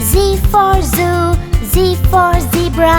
Phonics